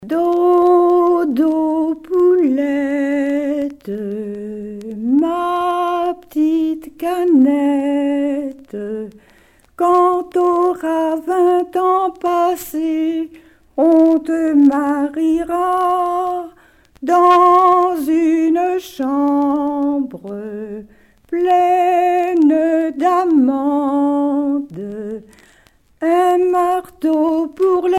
enfantine : berceuse
Pièce musicale éditée